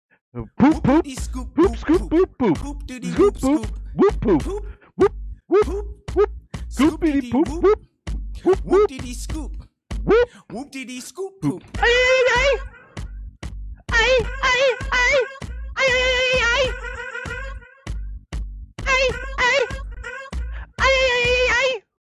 More Sounds in Music Soundboard